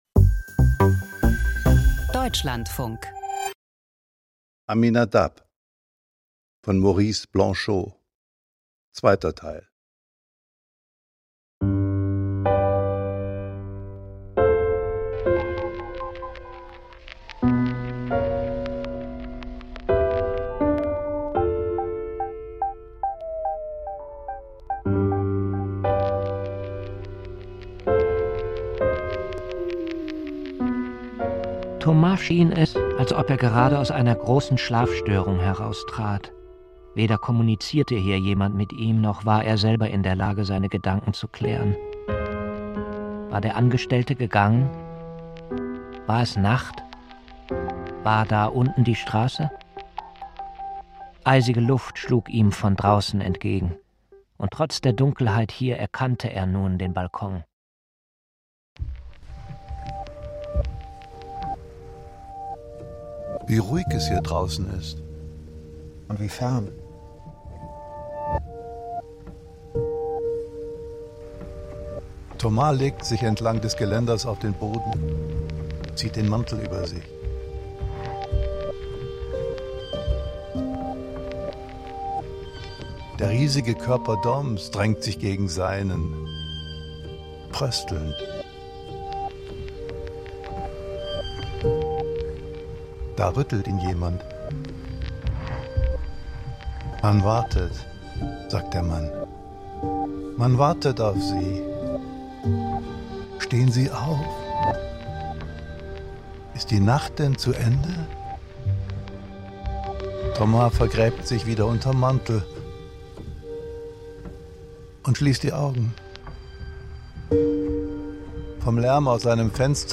Aminadab (2) – Hörspiel nach Maurice Blanchot